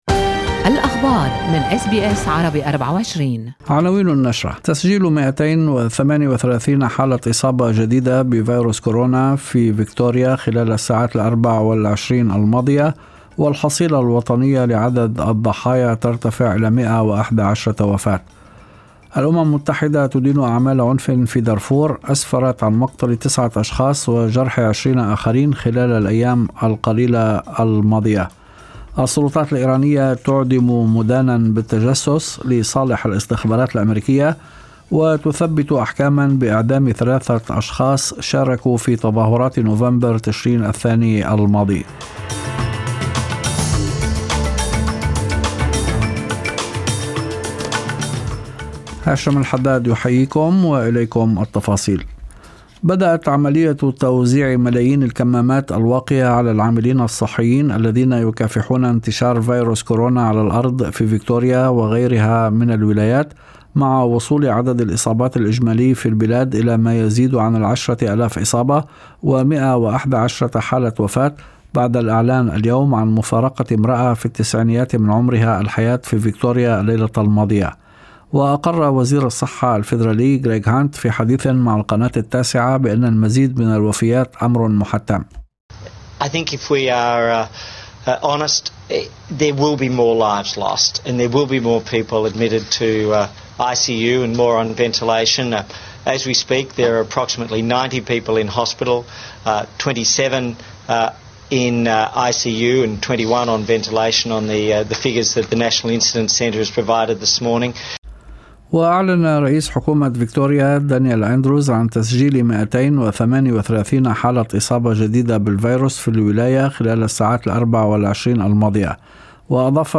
نشرة أخبار المساء 15/7/2020